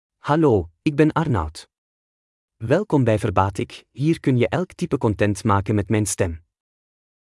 Arnaud — Male Dutch (Belgium) AI Voice | TTS, Voice Cloning & Video | Verbatik AI
MaleDutch (Belgium)
Voice sample
Male
Dutch (Belgium)